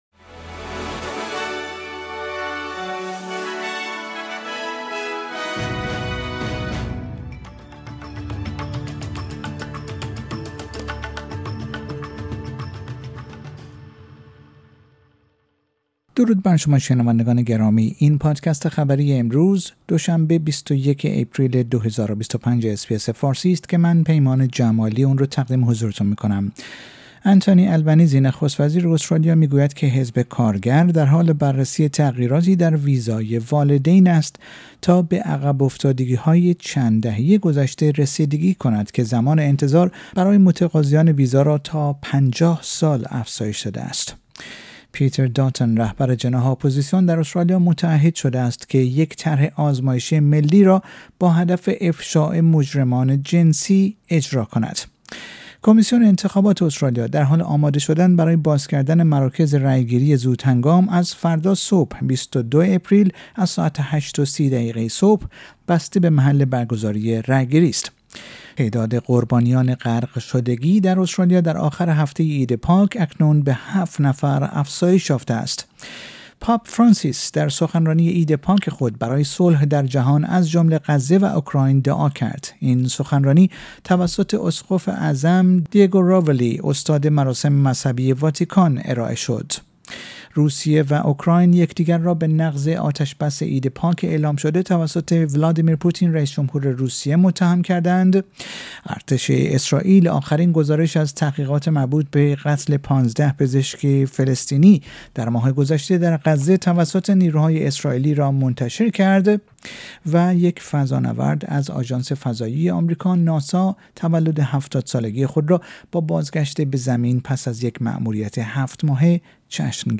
در این پادکست خبری مهمترین اخبار امروز دوشنبه ۲۱ آپریل ارائه شده است.